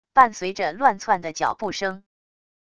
伴随着乱窜的脚步声wav音频